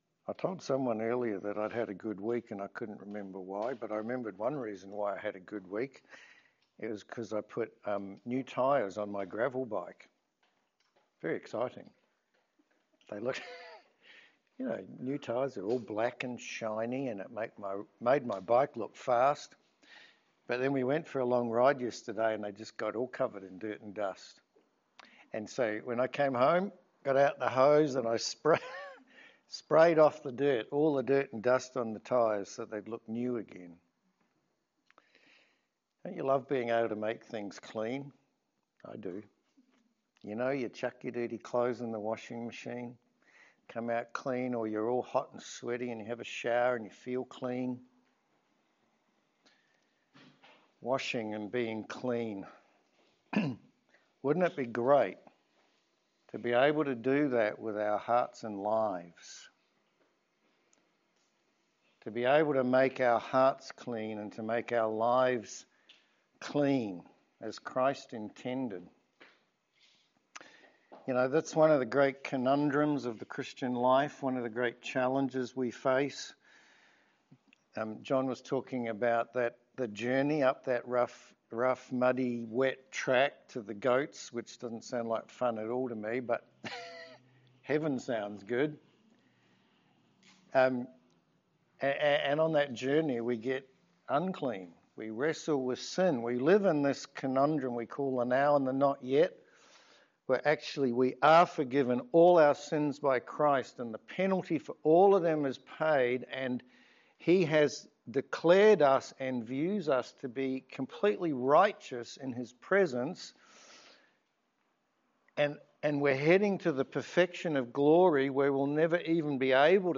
Romans 7:1-12 Service Type: Sermon How does Christ intend Christians grow in godliness?